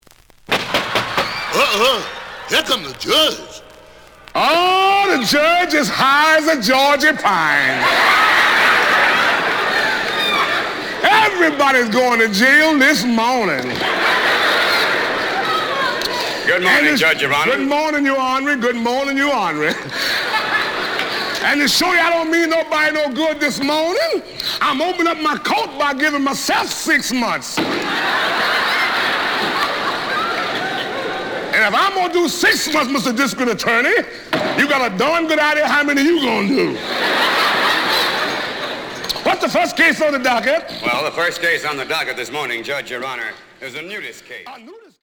The audio sample is recorded from the actual item.
●Genre: Funk, 60's Funk
Edge warp.